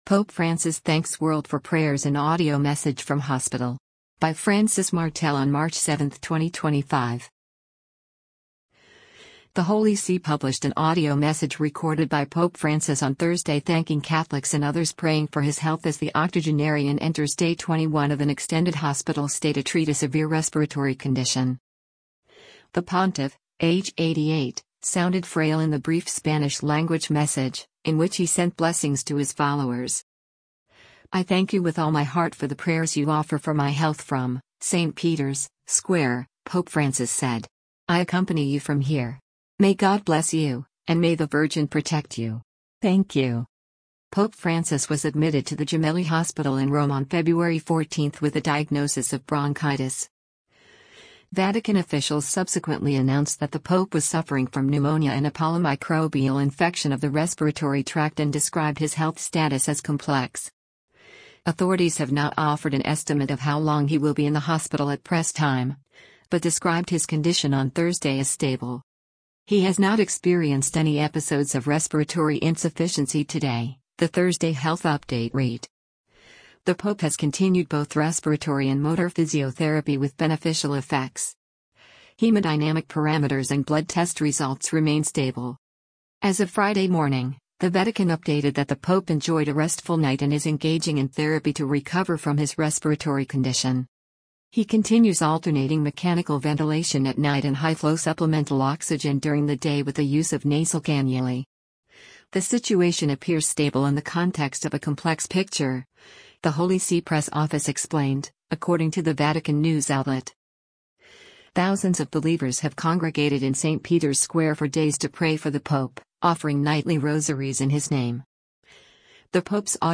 The Holy See published an audio message recorded by Pope Francis on Thursday thanking Catholics and others praying for his health as the octogenarian enters day 21 of an extended hospital stay to treat a severe respiratory condition.
The pontiff, age 88, sounded frail in the brief Spanish-language message, in which he sent blessings to his followers.